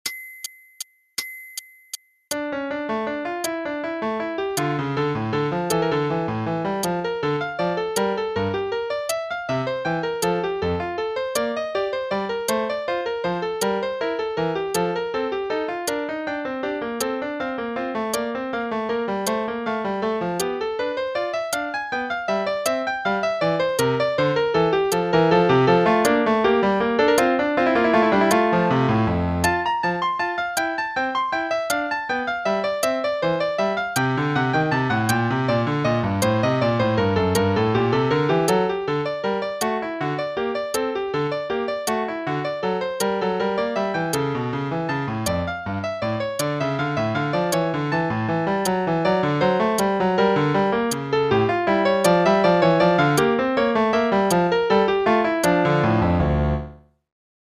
Play-along: Prelude in D minor (en=140)